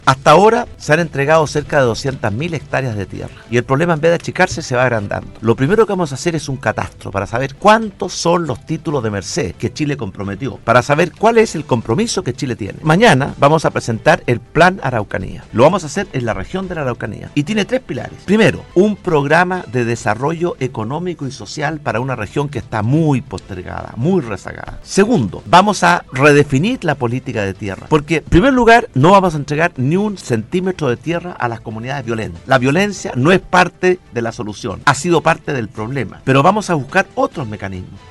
Agradeciendo en Osorno el cariño de cientos de personas que ayer llegaron a respaldar con un banderazo su postulación presidencial, el candidato por el bloque Chile Vamos, Sebastián Piñera, se dirigió a la audiencia de Radio Sago y en una entrevista en profundidad, abordó respaldado por propuestas, temas relevantes como su compromiso con la clase media y los jubilados.